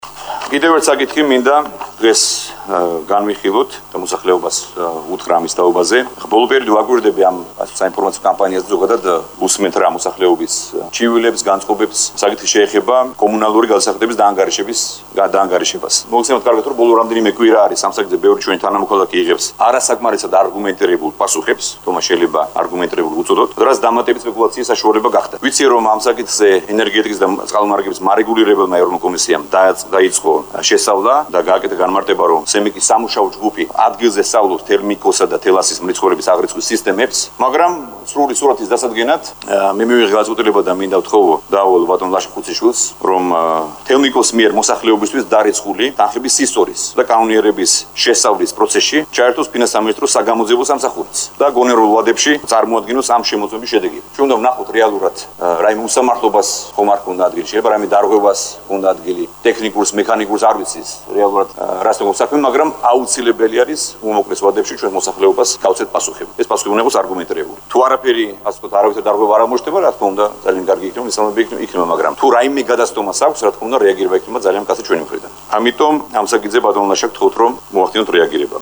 ირაკლი ღარიბაშვილის ხმა